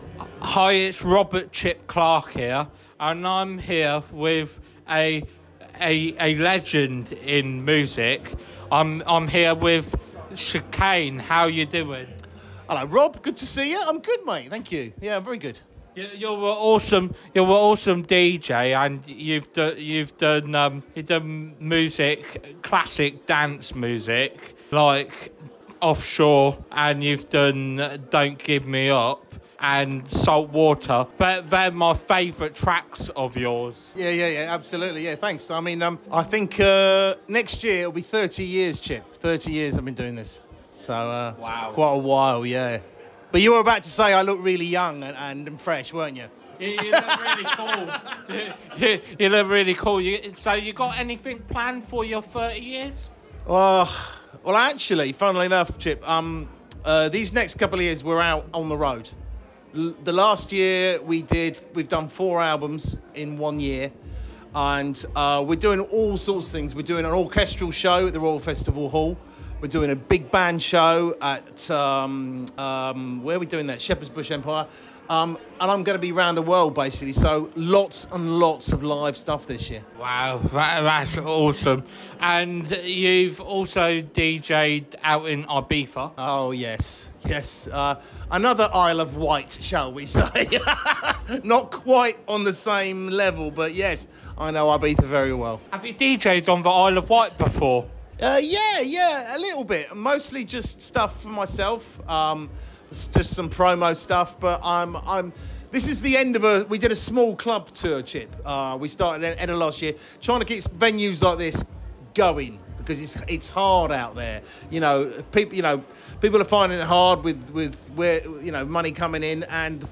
Chicane Interview 2025